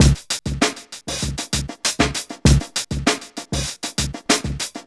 FUNKYB 98.wav